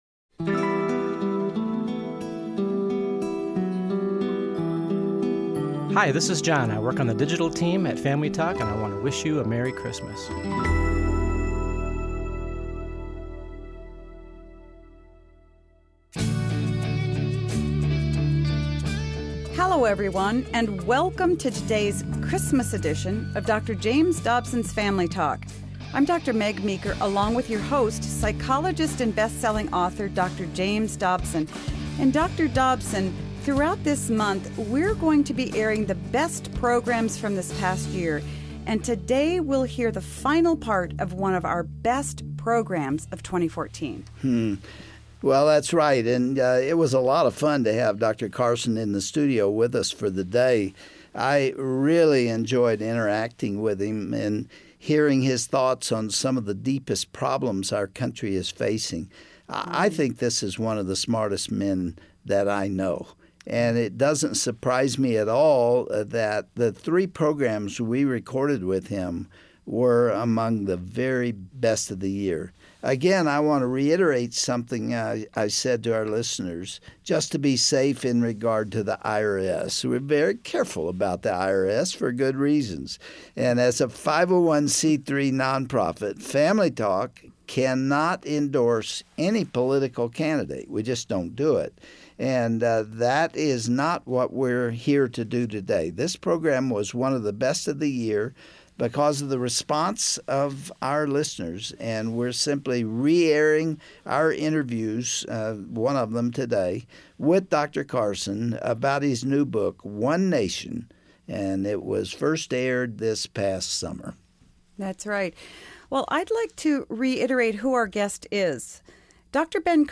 Dr. James Dobson interviews world renown pediatric neurosurgeon Dr. Ben Carson about his story and the future of America.